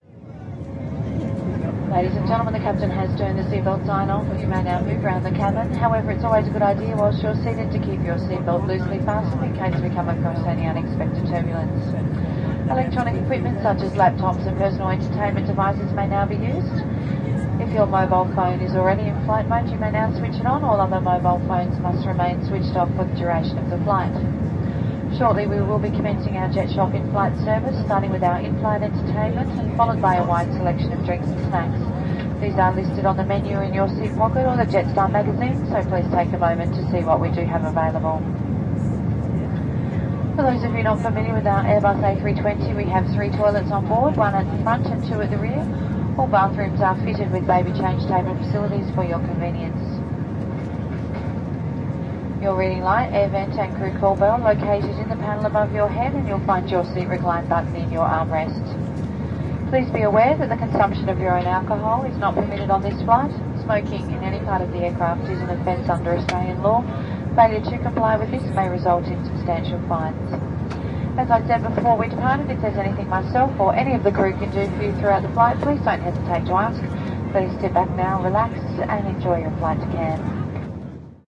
描述：飞行服务员阻止了这一乐趣：（ 录音链。Edirol R09HR（内部麦克风）
标签： 飞机 飞机 飞机 气氛 机舱公告 现场记录 喷气星
声道立体声